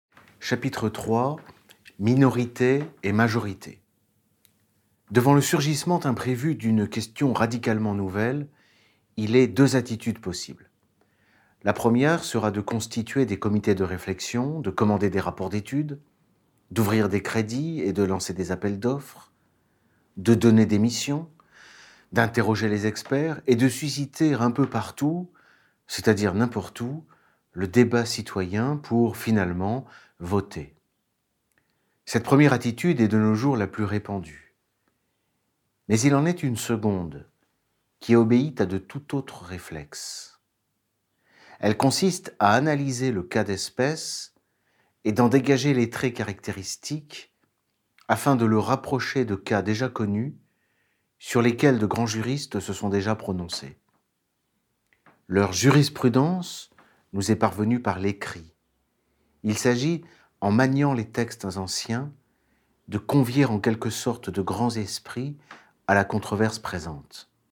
Pédophilie, viol et séduction – Livre audio